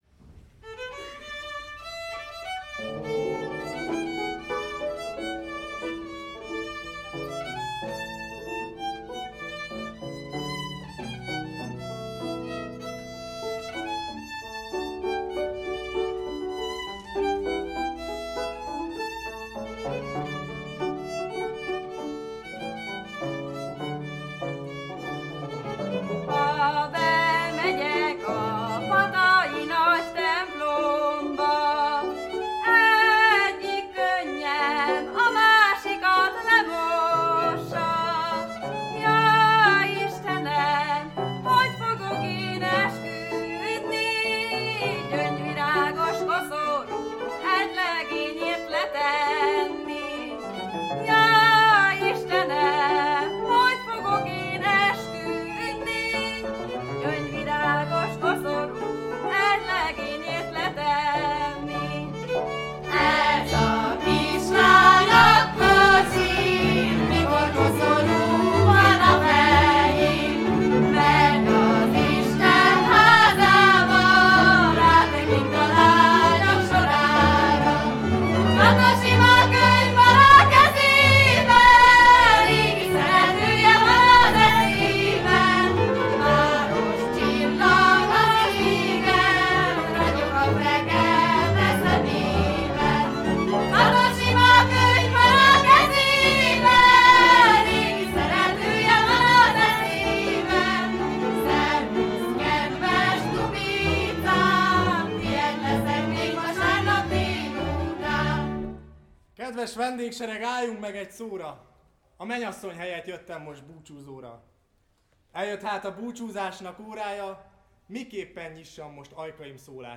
“Vagy holtig örömre vagy örökös búra” – gyöngyöspatai lakodalmas